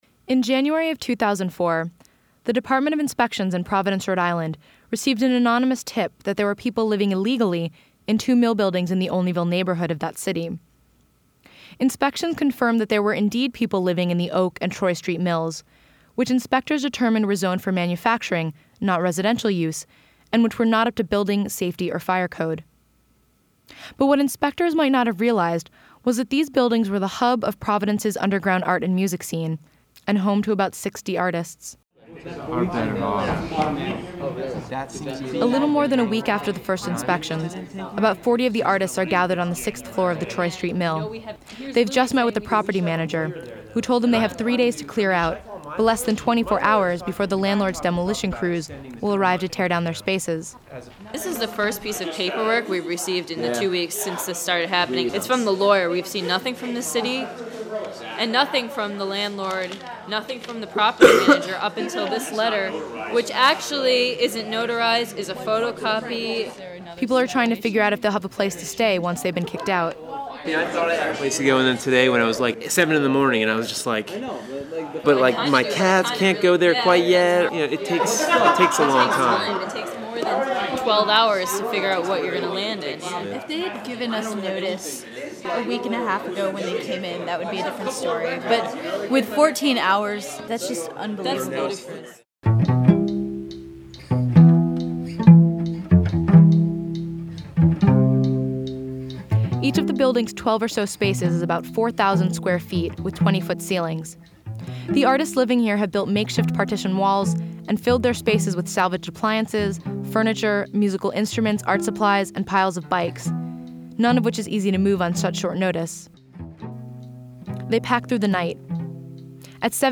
An hour-long radio documentary about the renovation of mill buildings in the poorest neighborhoods of the “Renaissance City,” and the subsequent displacement of artists and small businesses.
This hour-long story includes interviews with Providence city officials, developers, artists from the now legendary Fort Thunder collective, activists, historic preservationists and city residents.